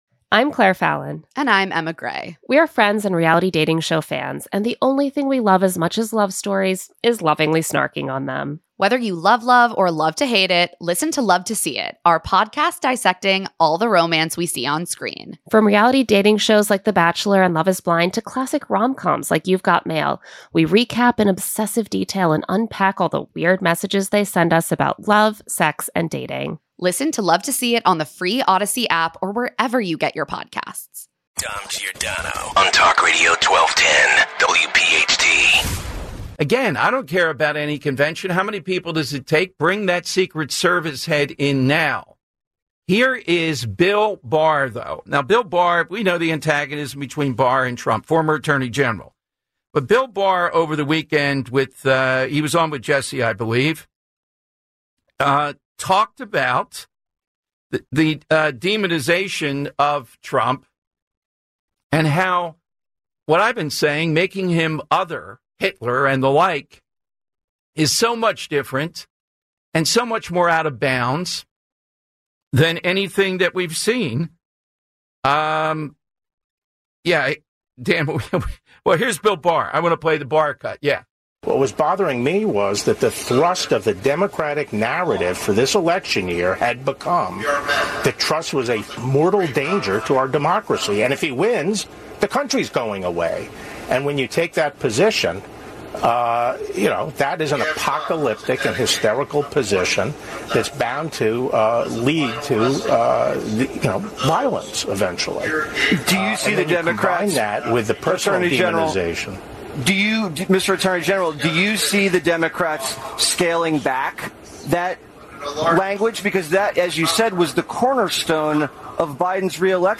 Full Hour